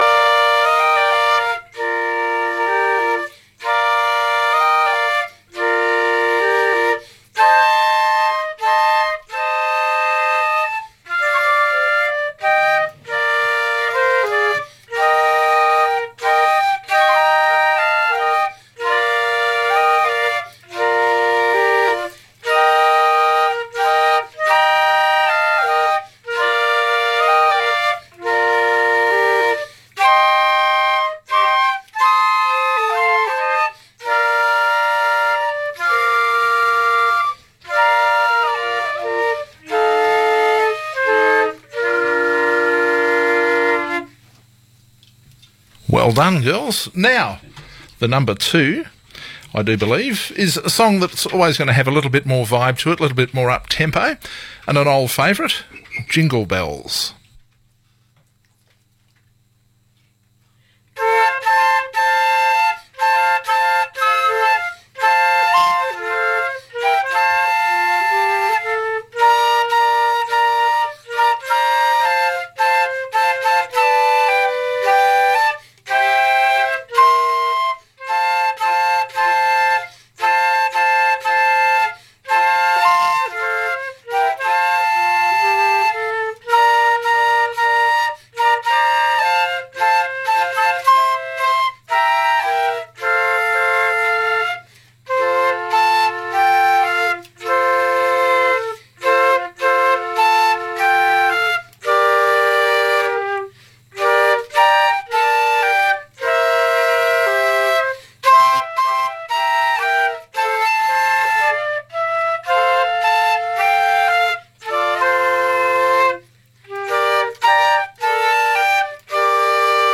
High Schools Christmas Music Competition